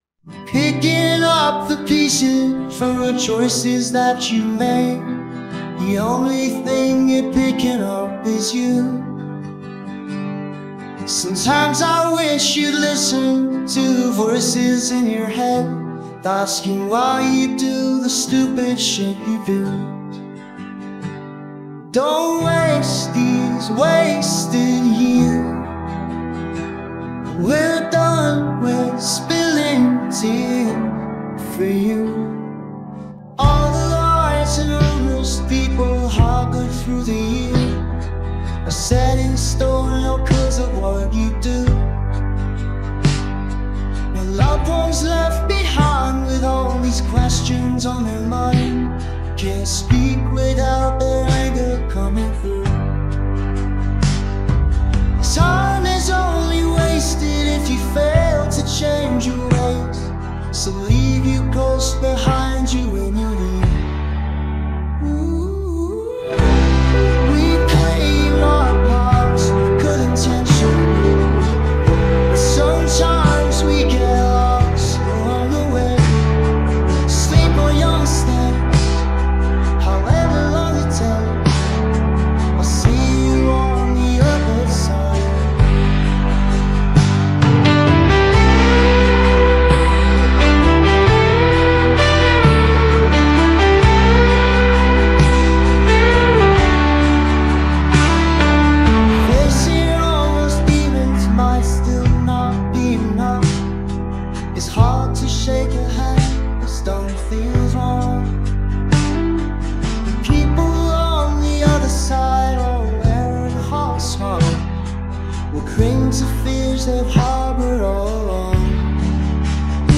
UNA BALLAD STRUGGENTE